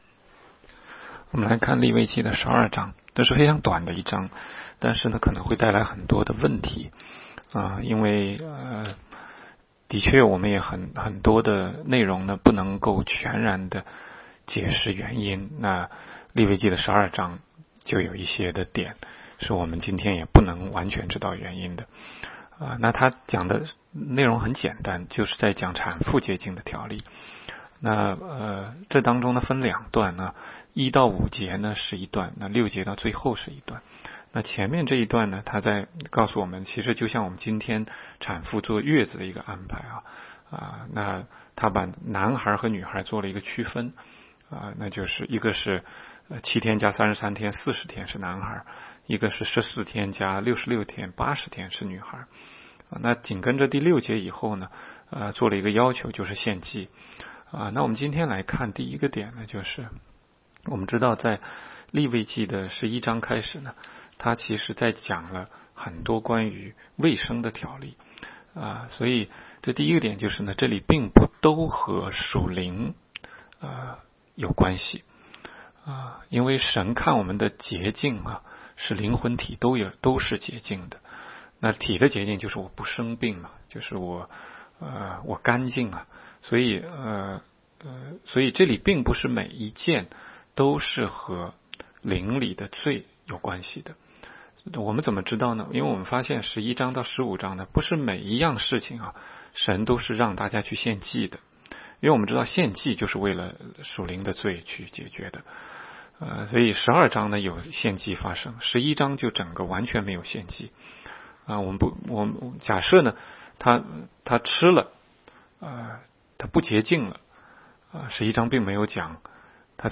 16街讲道录音 - 每日读经-《利未记》12章